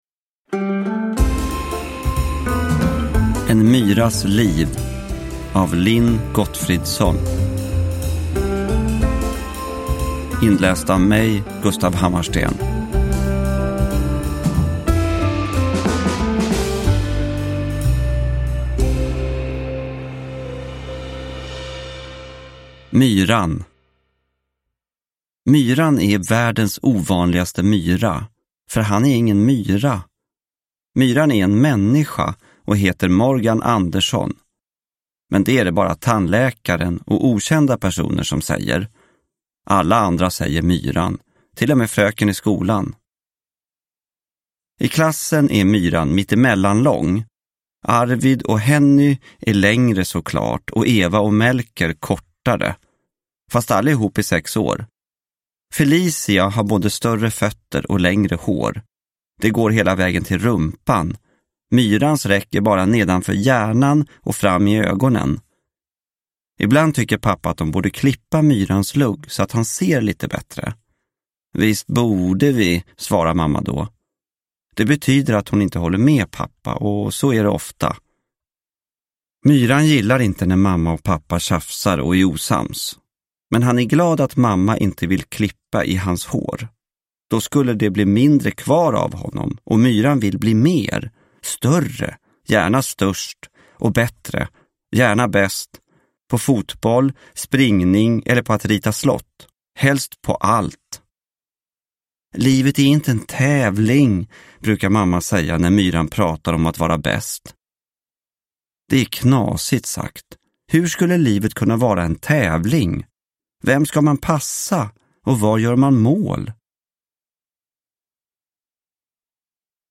Nedladdningsbar ljudbok